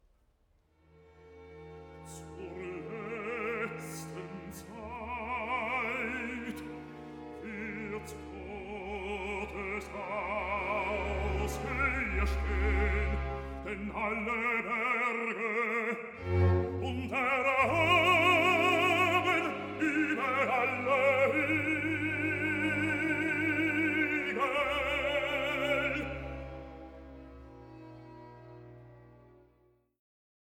Rezitativ (Jeremias), Chor